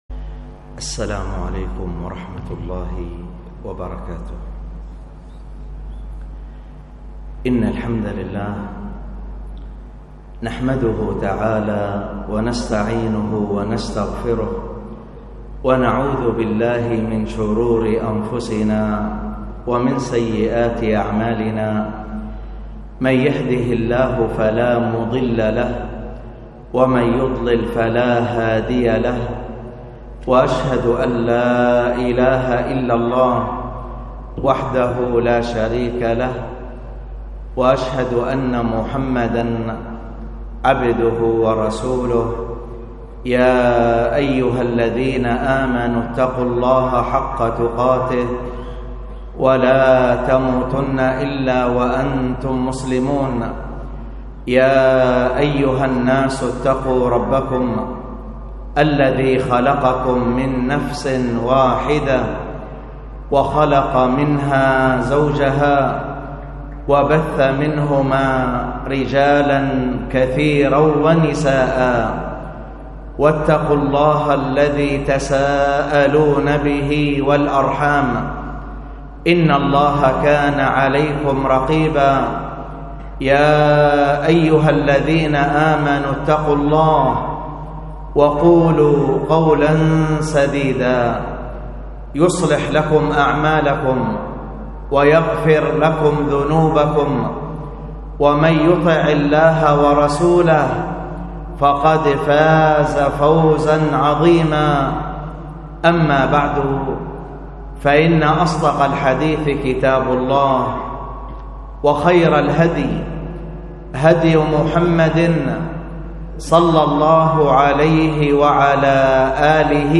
الخطبة بعنوان احفظ الله يحفظك، وكانت بمسجد السنة بالمركز الجديد بتبالة ٢ ذو القعدة ١٤٣٧هـ ألقاها